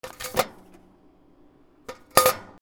/ M｜他分類 / L01 ｜小道具